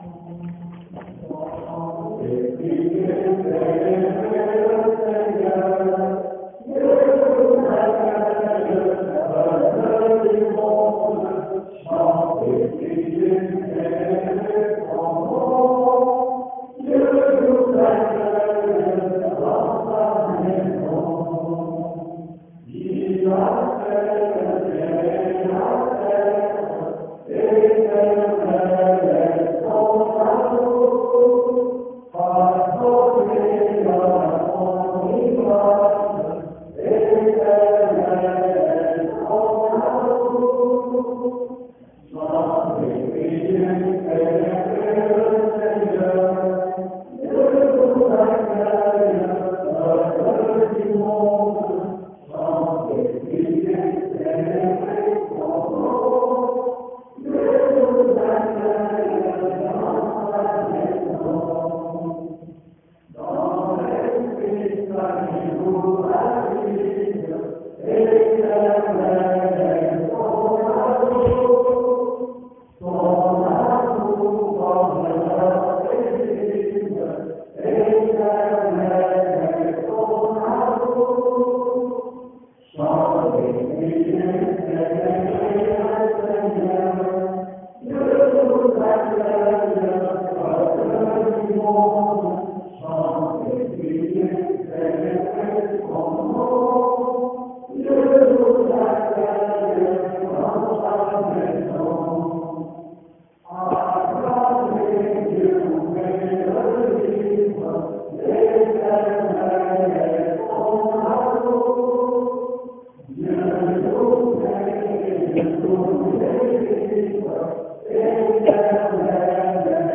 Sons enregistrés lors de la cérémonie :
chant (chanter, célébrer le Seigneur)